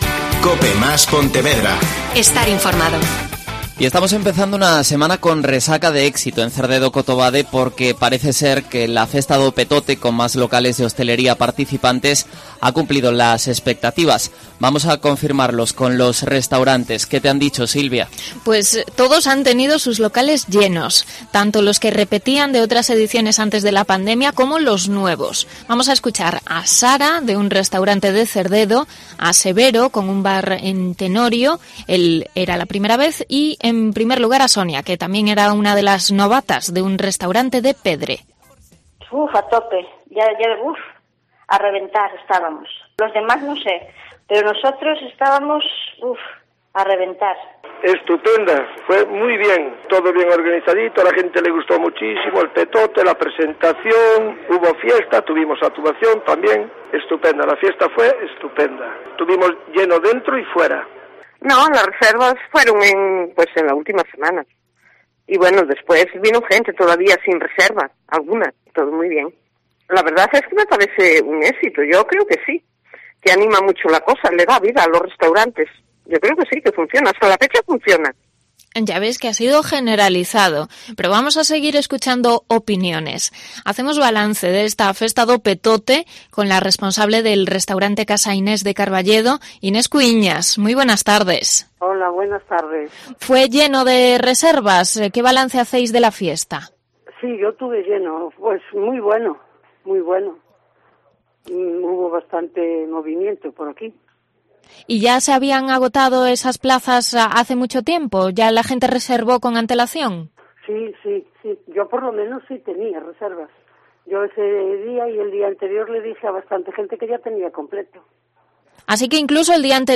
Declaraciones a COPE de la hostelería de Cotobade y Cerdedo sobre la Festa do Petote